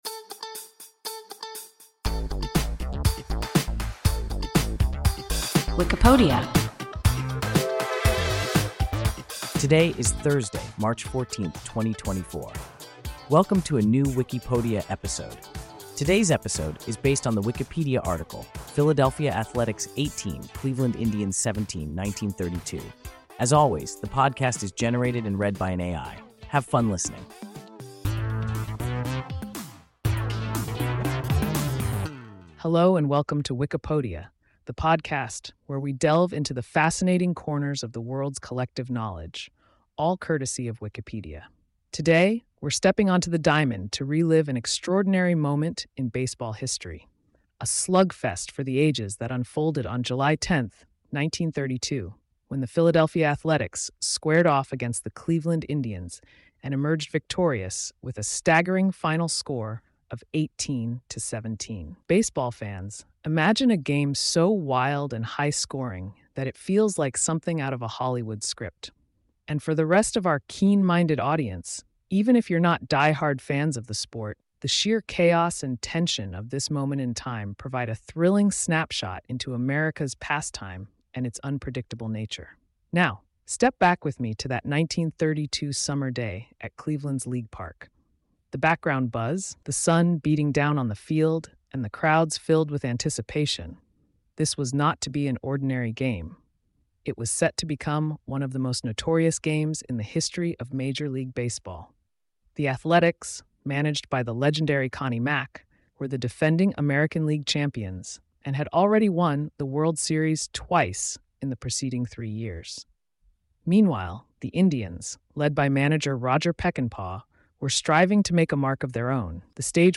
Philadelphia Athletics 18, Cleveland Indians 17 (1932) – WIKIPODIA – ein KI Podcast